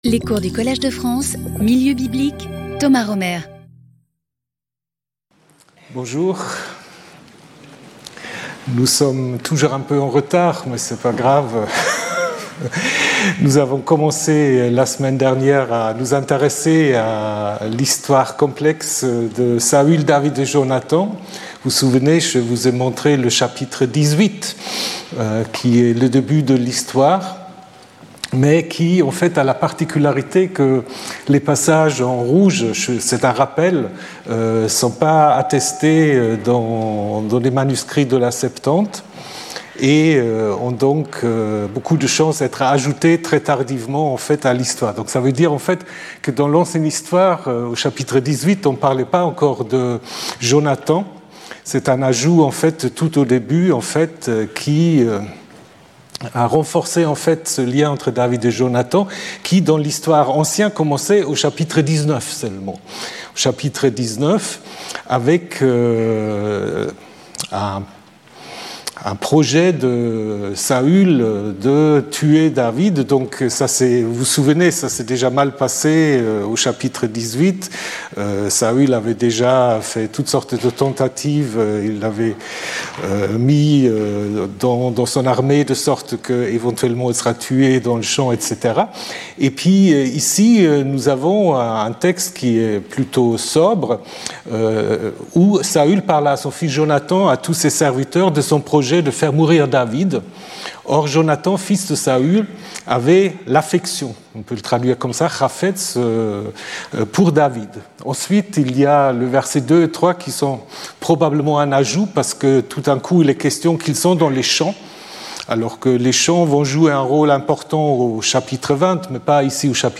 She succeeds, but Samuel's message is not what Saul expected. Speaker(s) Thomas Römer Professor and Administrator of the Collège de France Events Previous Lecture 12 Feb 2026 14:00 to 15:00 Thomas Römer Saul, David, Solomon: mythical or historical figures?